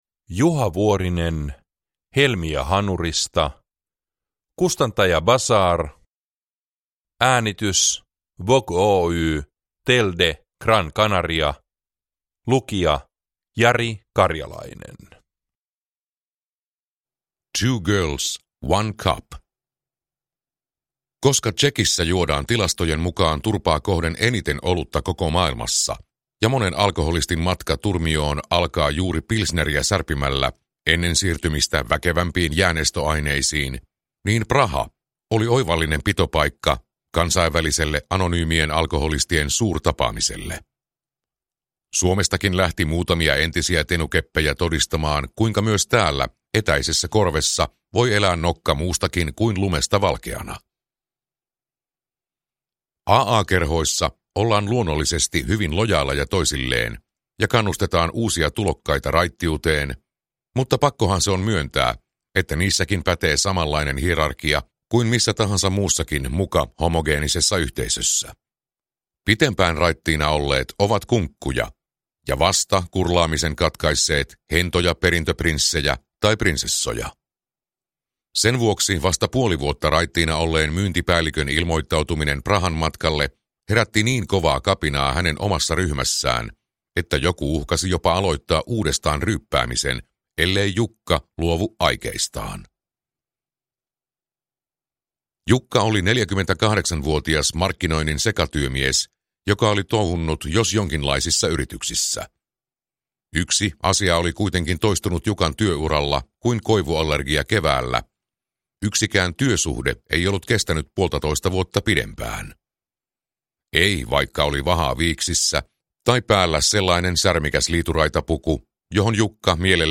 Helmiä hanurista – Ljudbok